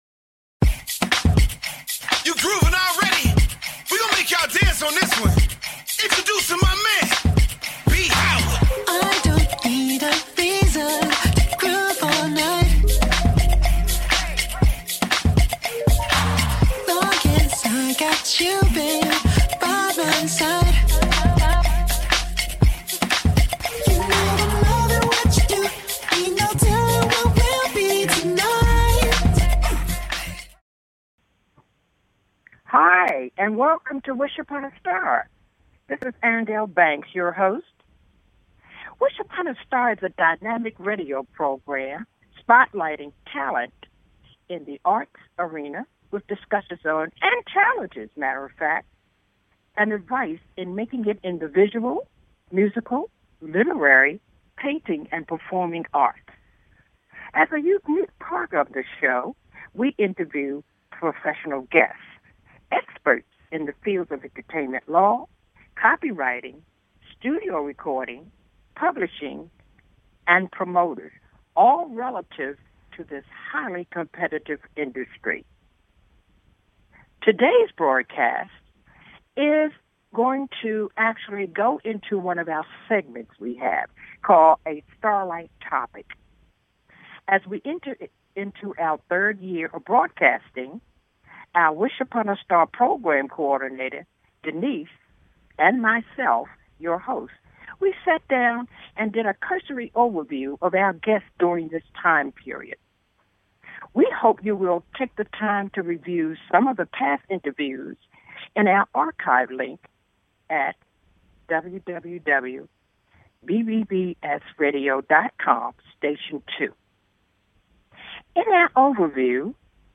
Conversations discussing the challenging elements of visual, musical, literary, painting, and the performing arts. We will interview professional guests in the fields of entertainment law, copyrighting, studio recording, songwriting, publishing, and other topics in these highly creative and challenging fields."